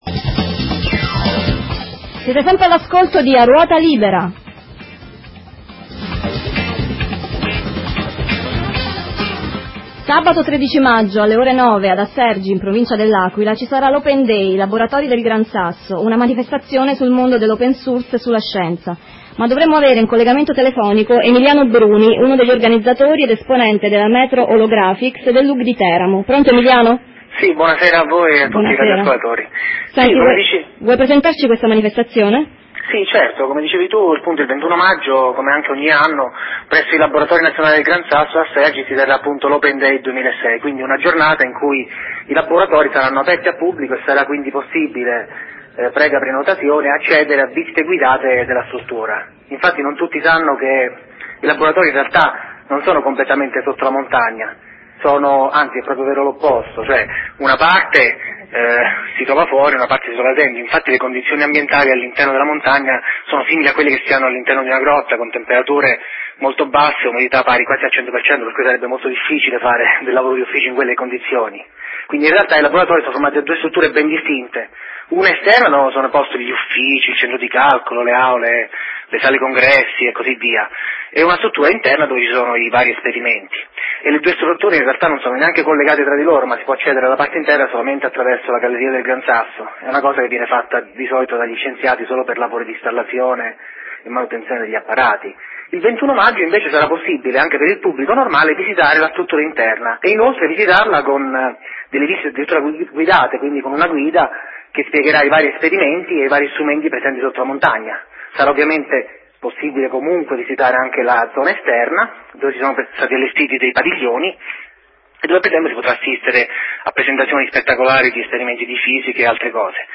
Intervista radiofonica del 11 maggio 2004 nel programma "A ruota libera"
Occhio, l'annunciatrice per ben due volte dice che l'evento si terrà sabato 13 maggio. In realtà la data giusta è quella che dico io, Domenica 21 Maggio 2006.